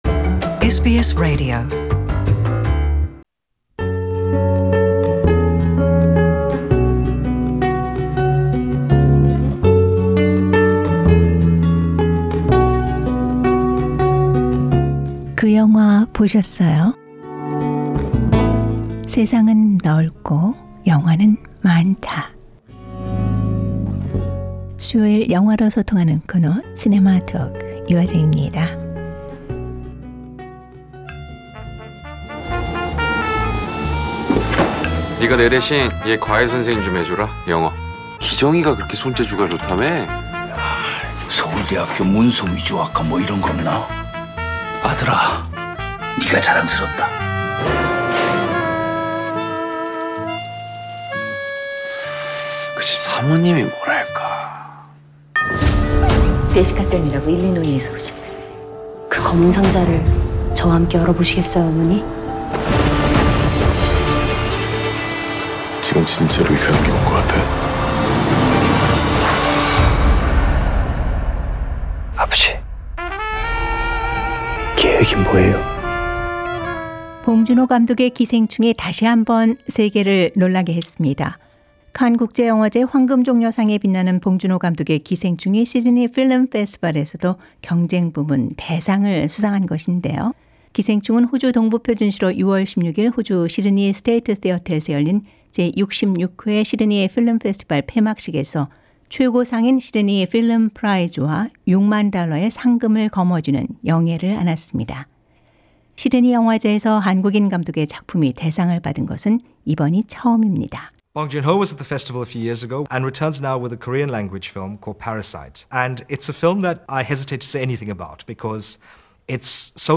저희 sbs 한국어 프로그램은 지난 13일 시드니 필름 페스티벌 현장을 찾아 봉준호 감독과의 특별 대담을 가졌습니다.